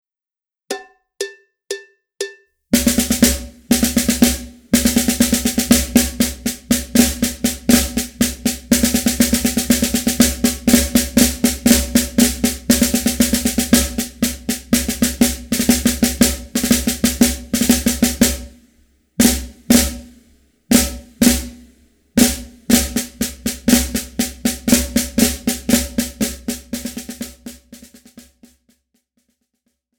Besetzung: Schlagzeug
Rudimental-Solos